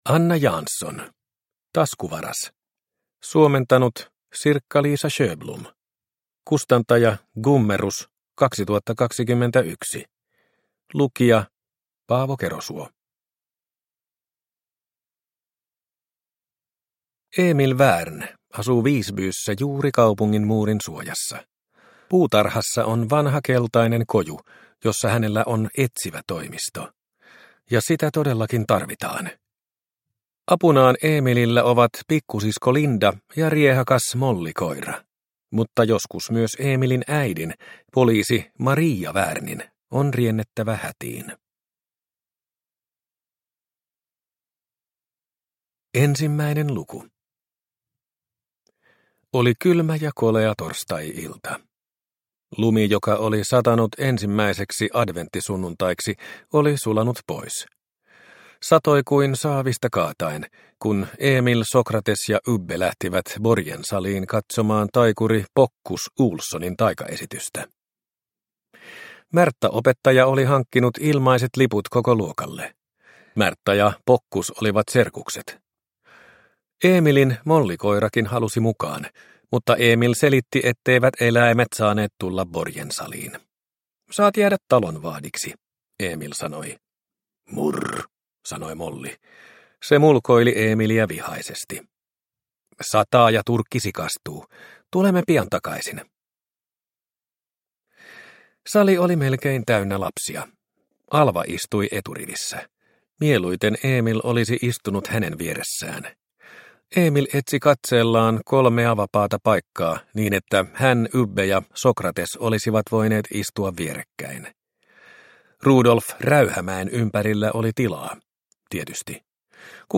Taskuvaras – Ljudbok – Laddas ner